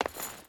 Footsteps / Stone / Stone Chain Run 2.wav
Stone Chain Run 2.wav